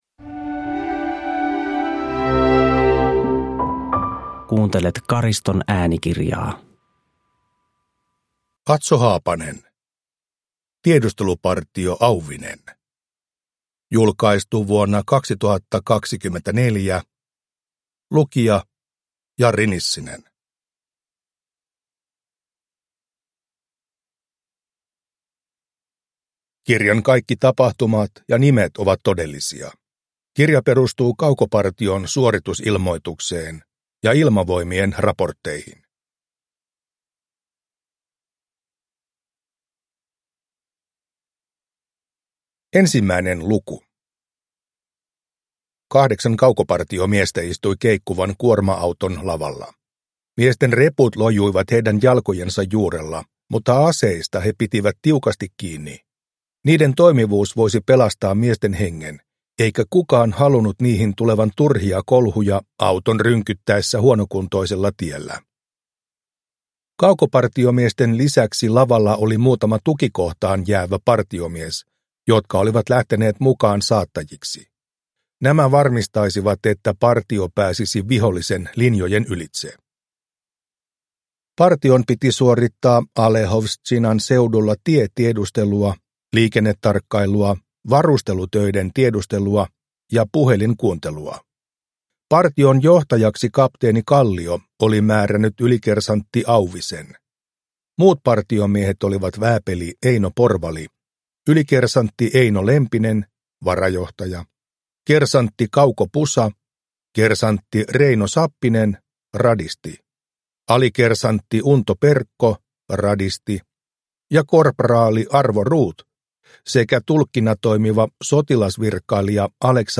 Tiedustelupartio Auvinen – Ljudbok